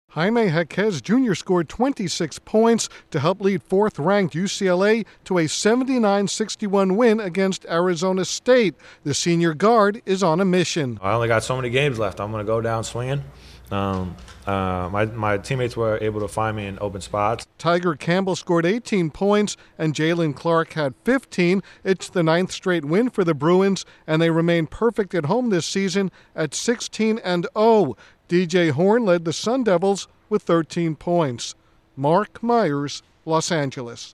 UCLA stretches its winning streak by thumping Arizona State. Correspondent